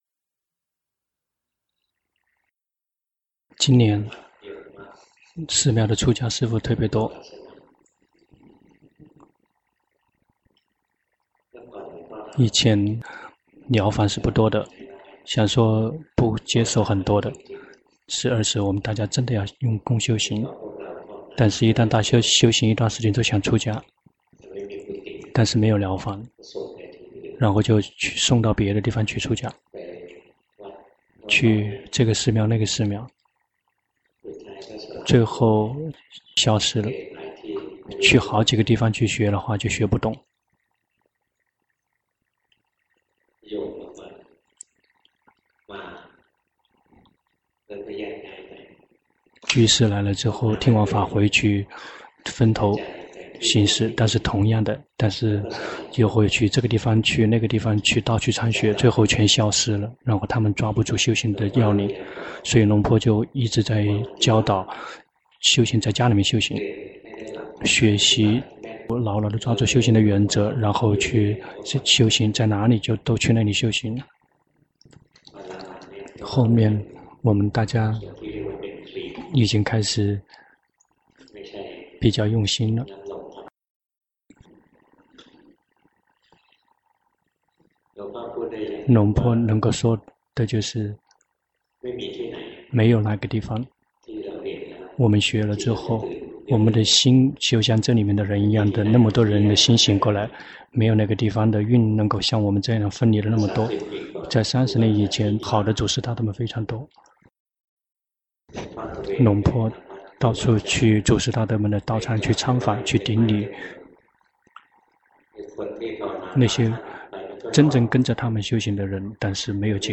長篇法談｜安般念的修法